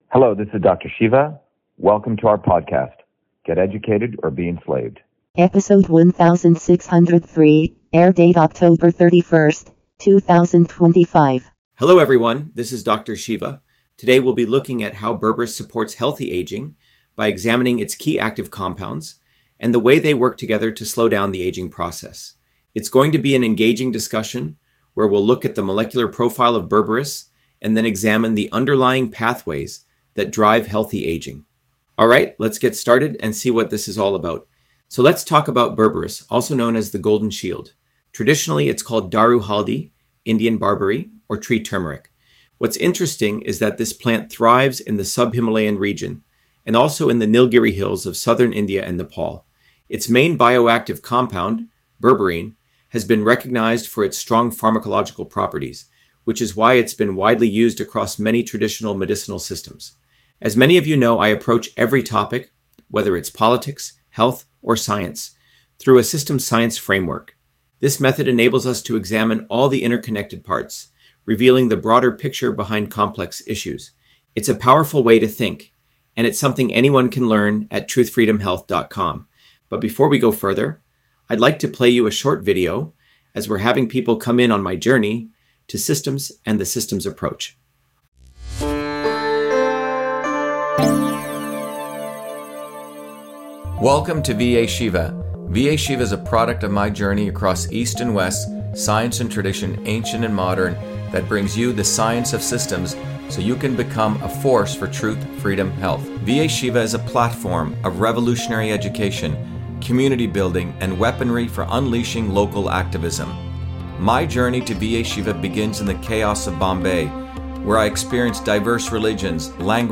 In this interview, Dr.SHIVA Ayyadurai, MIT PhD, Inventor of Email, Scientist, Engineer and Candidate for President, Talks about Berberis on Aging: A Whole Systems Approach